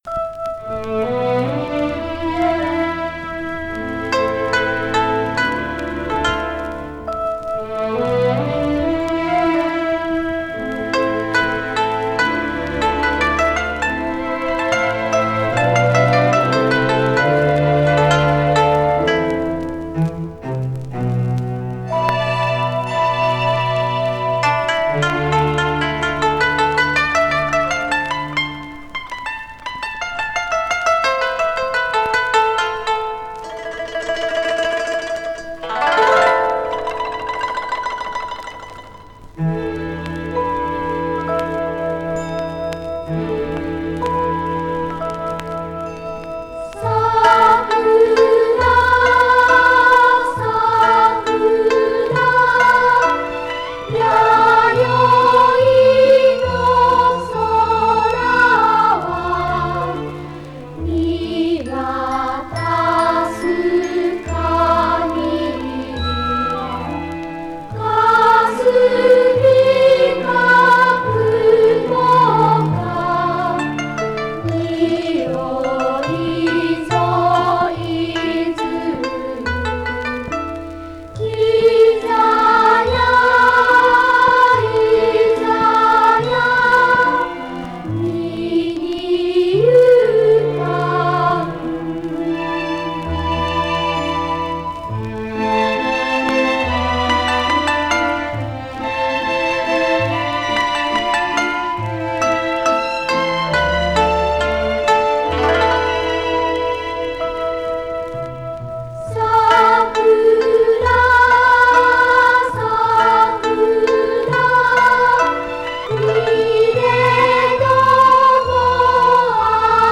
Жанр: Enka
в сопровождении традиционного японского инструмента кото.
Koto
Soprano Vocals